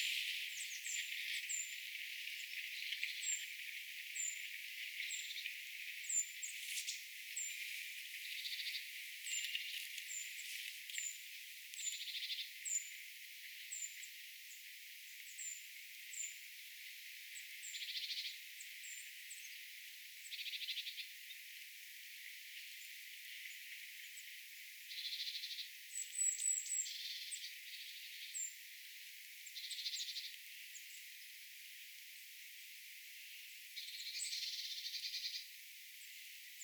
parvessa oli ti-puukiipijä
ti-puukiipija.mp3